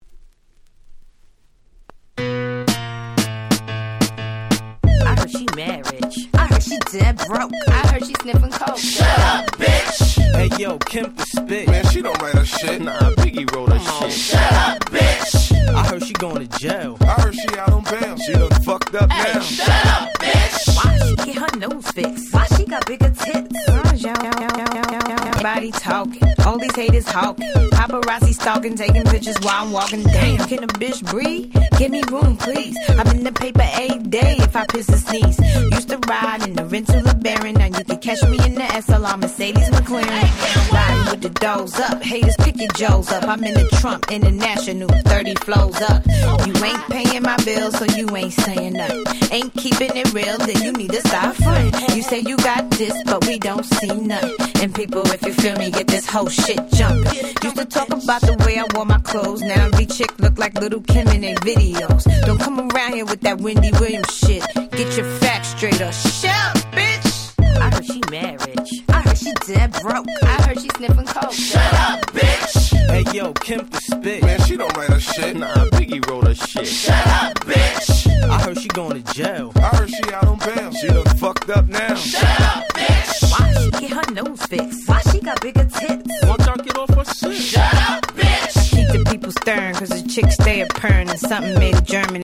05' Smash Hit Hip Hop !!
彼女らしい攻撃的なフックがたまりません！
当時はここ日本のClubでも頻繁にPlayされていたクラブヒットチューンです。